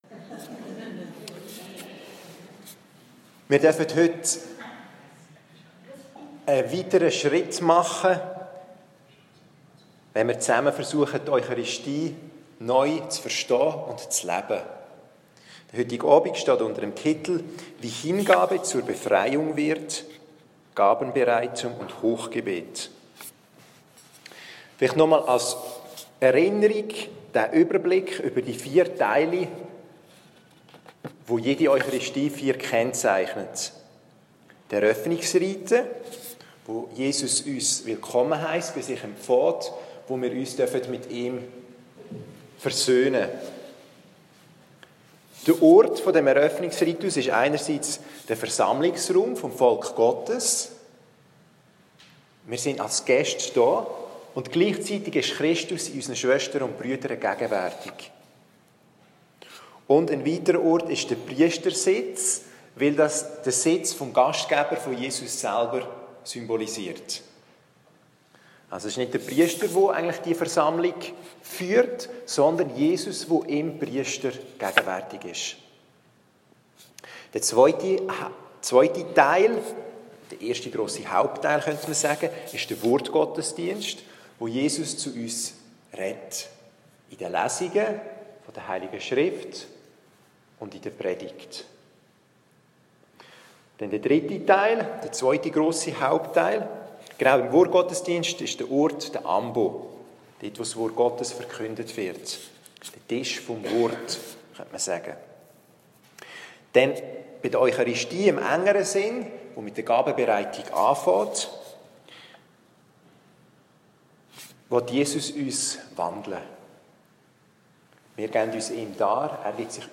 Hier finden Sie einige unserer Glaubenskurse, die live vor Ort aufgezeichnet wurden.